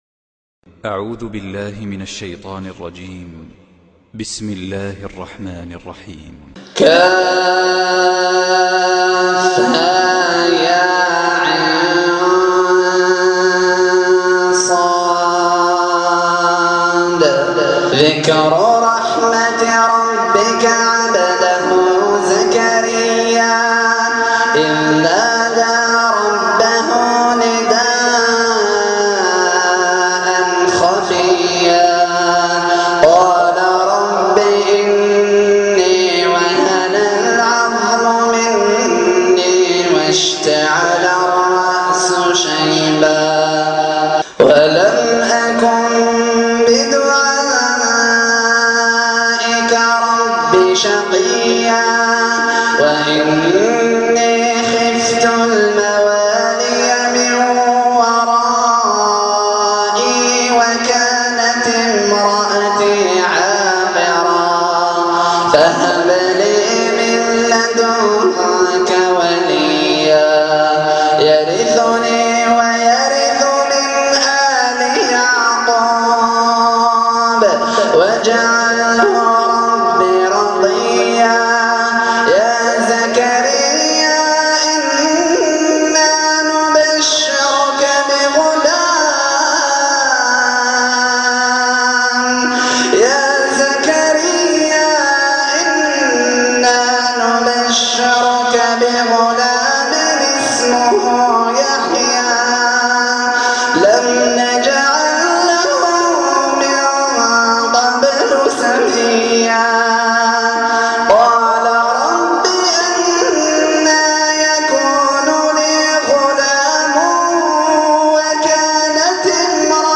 تلاوة رائعة من صلاة التهجد